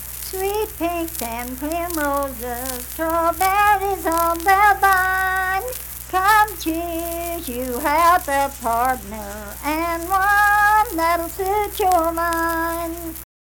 Unaccompanied vocal music performance
Verse-refrain 1(4).Born February 9, 1874 in Wayne County, West Virginia.
Voice (sung)